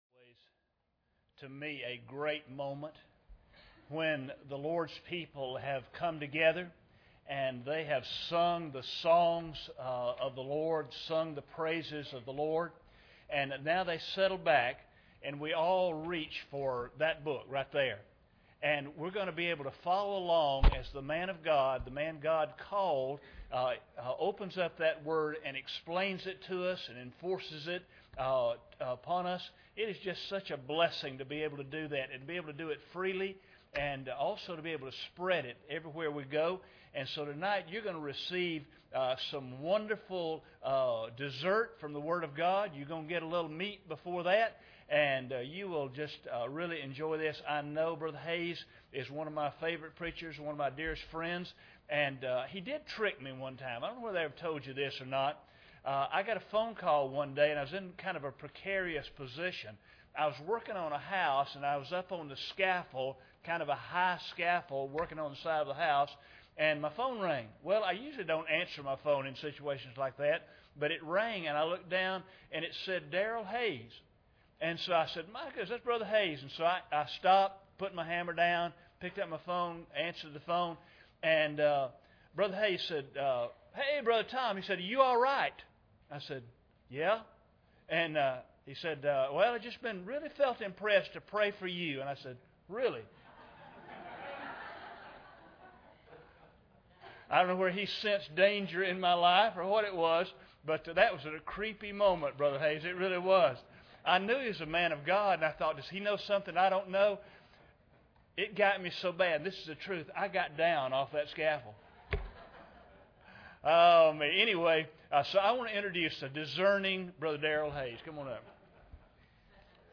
Matthew 15:32-38 Service Type: Revival Service Bible Text